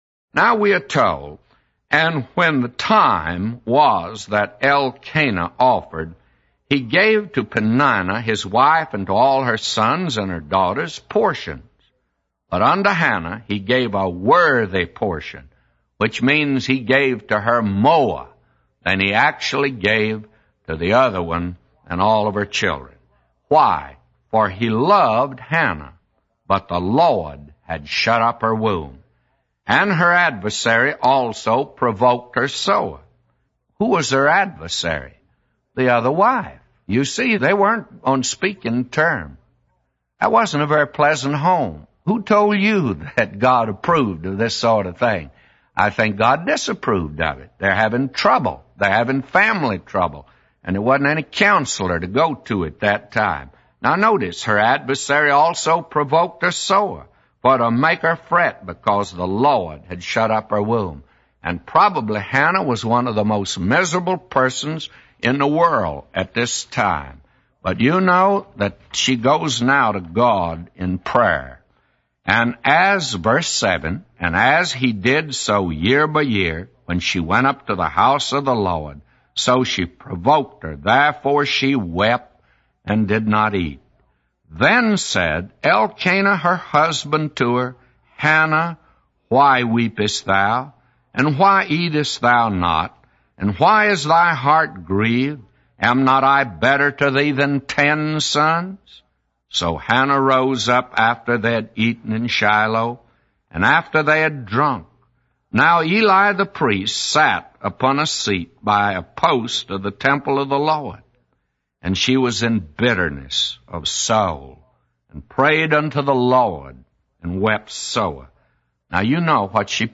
A Commentary By J Vernon MCgee For 1 Samuel 1:4-999